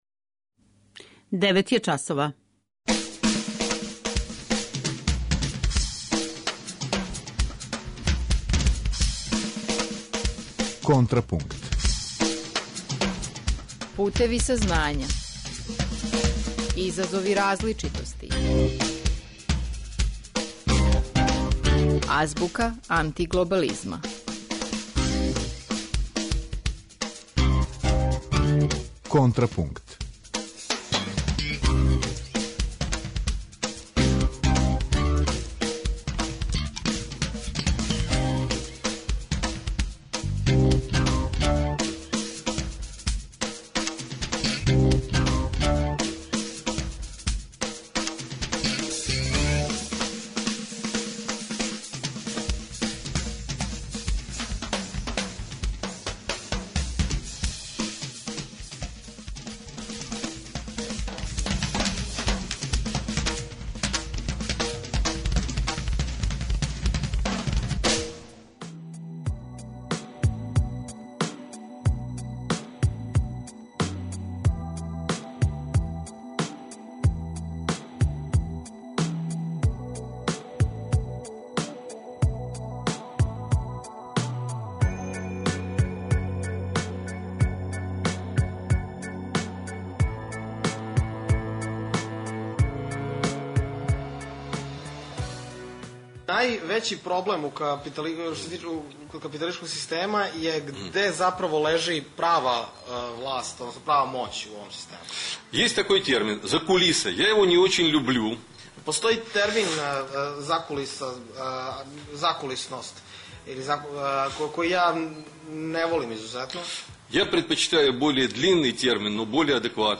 Eмитујемо интервју